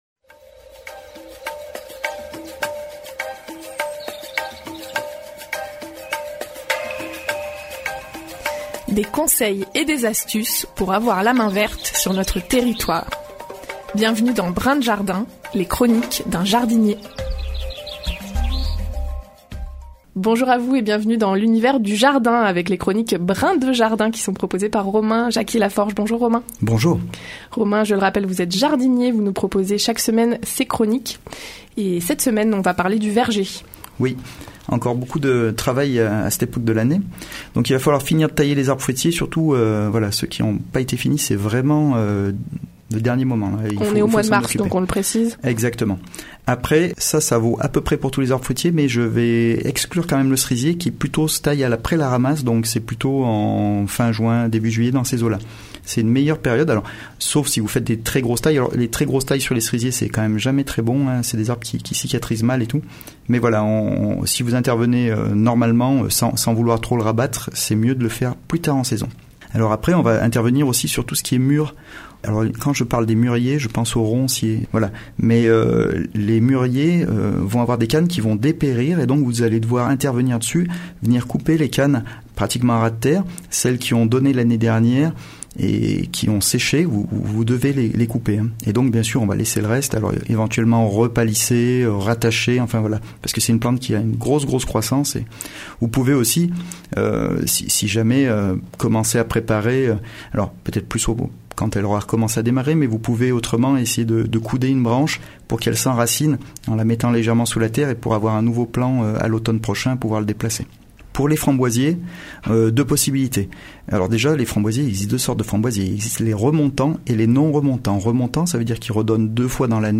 La chronique jardin hebdomadaire sur les ondes de Radio Royans Vercors